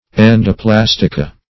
Search Result for " endoplastica" : The Collaborative International Dictionary of English v.0.48: Endoplastica \En`do*plas"ti*ca\, n. pl.